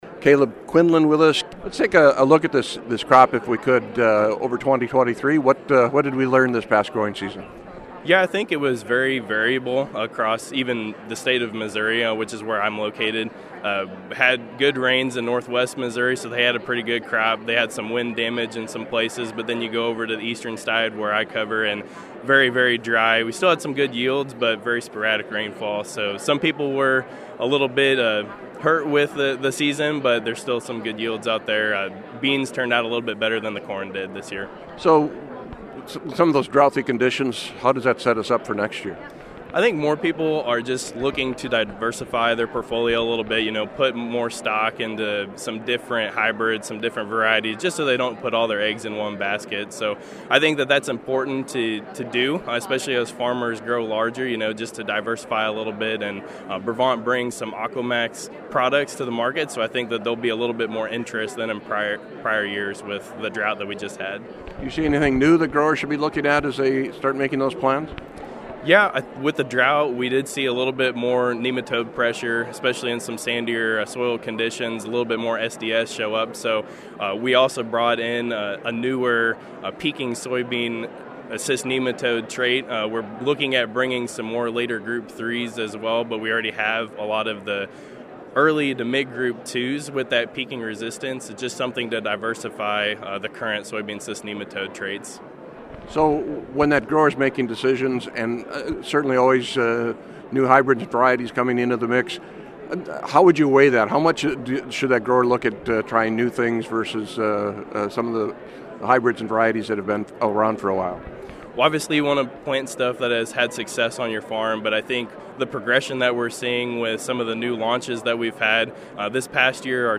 Here’s the full interview.